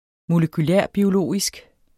Udtale molekylarbiologisk: [ moləkyˈlɑˀ- ]